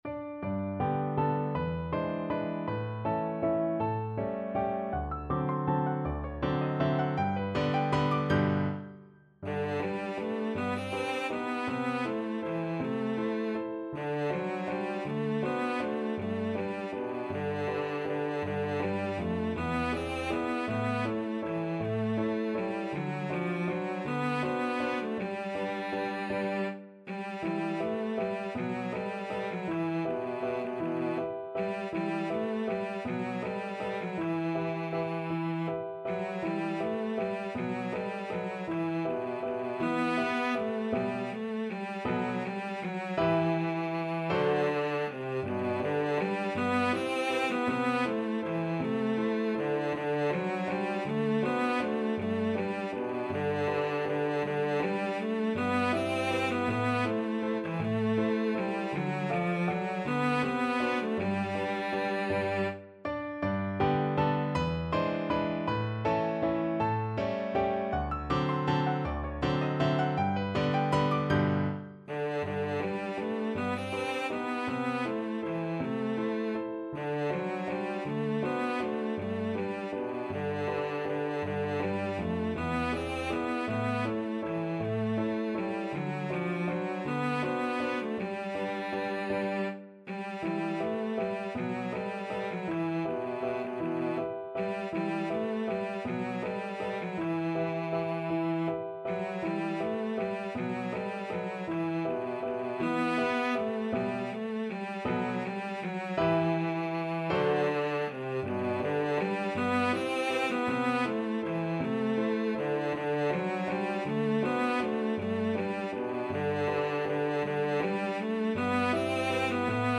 Cello
G major (Sounding Pitch) (View more G major Music for Cello )
3/4 (View more 3/4 Music)
~ = 160 Tempo di Valse
B3-C5
Traditional (View more Traditional Cello Music)